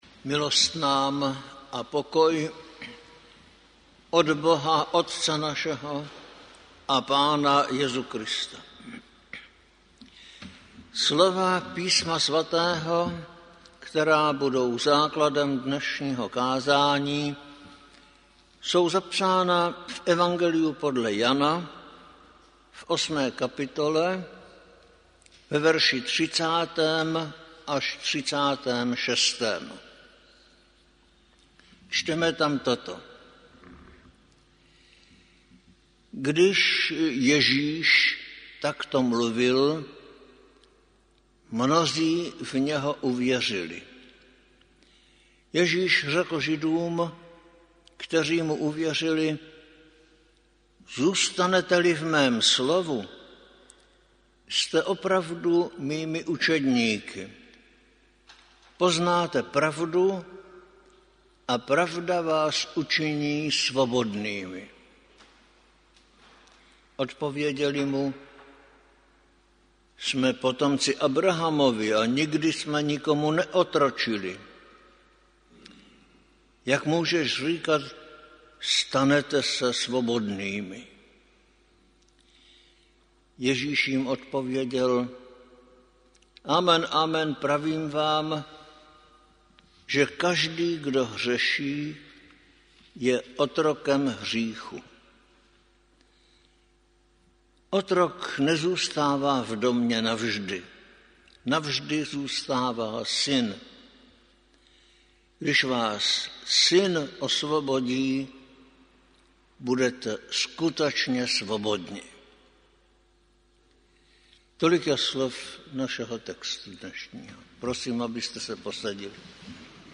Záznam kázaní z bohoslužeb.